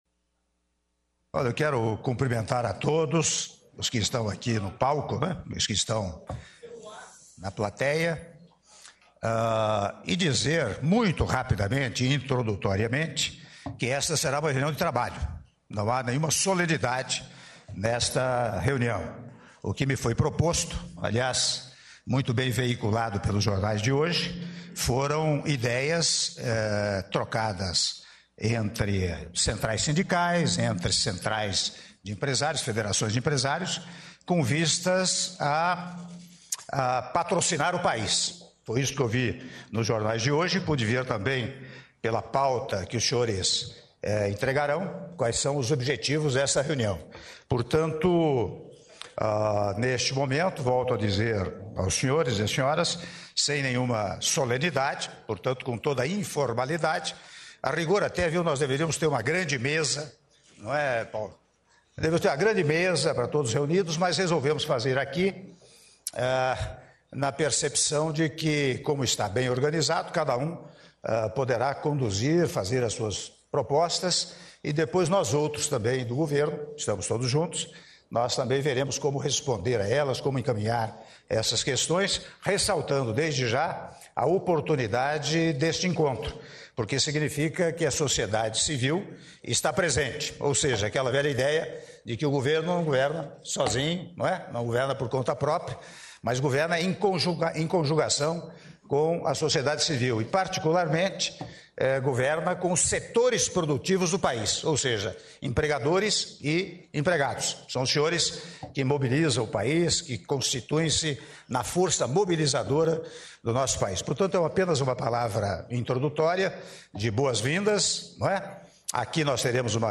Áudio do discurso do Presidente da República, Michel Temer, durante abertura da Reunião-Almoço com representantes do setor da indústria e centrais sindicais - Brasília/DF- (02min47s)